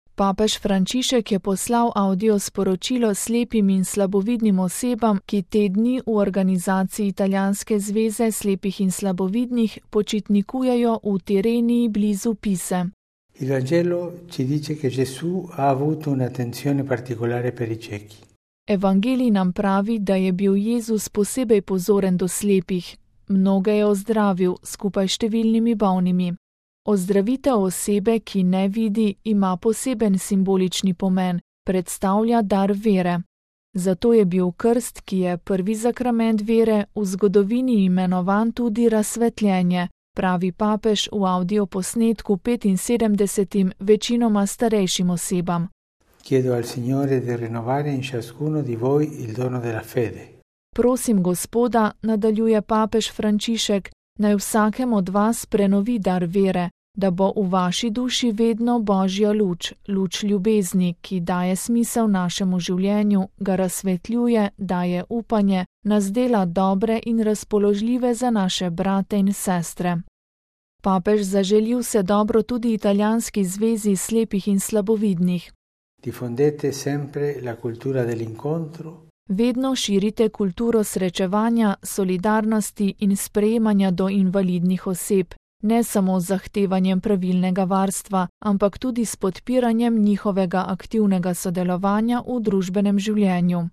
VATIKAN (torek, 11. junij 2013, RV) – Papež Frančišek je poslal avdio sporočilo slepim in slabovidnim osebam, ki te dni v organizaciji Italijanske zveze slepih in slabovidnih počitnikujejo v Tirreniji blizu Pise.